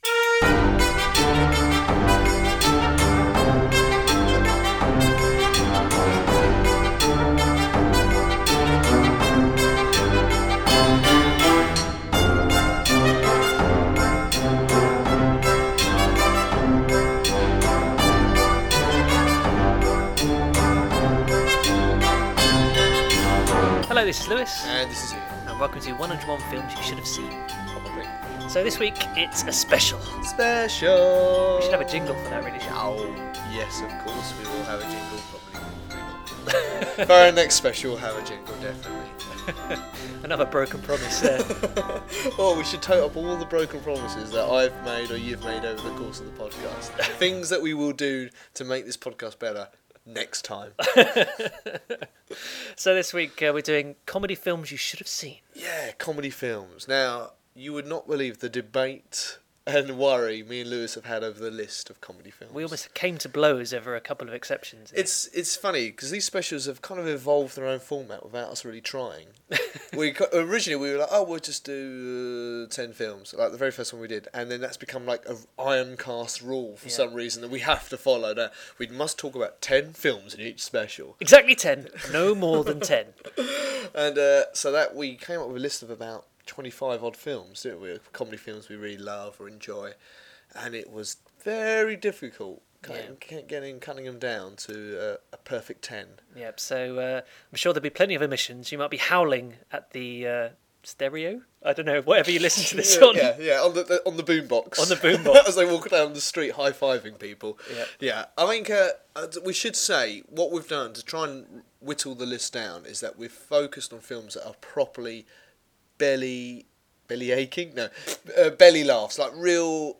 We've been looking forward to doing our comedy special, as it finally gives us the chance to spend half an hour quoting lines from our favourite comedy films, all the while laughing like schoolboys who've just read their first issue of Viz. As with our previous specials, we've chosen a list of our top ten…